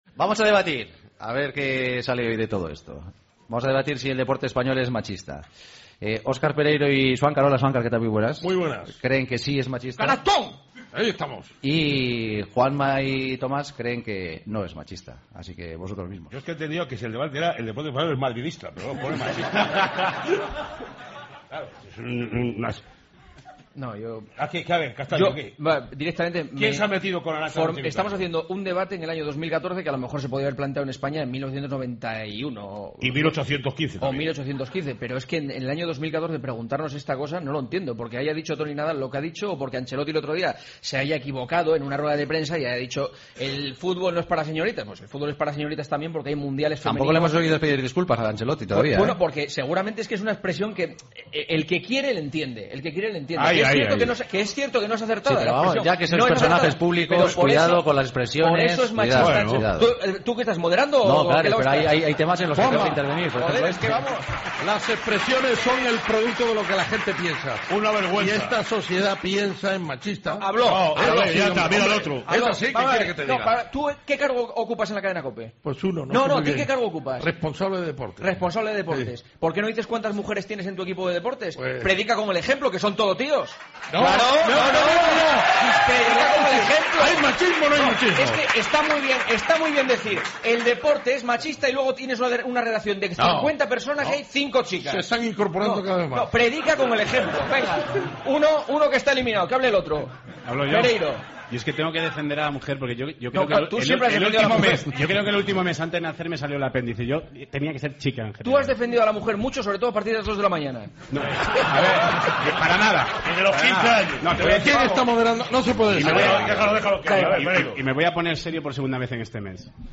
El debate de los jueves: ¿Es machista el deporte español?